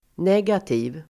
Uttal: [n'eg:ati:v]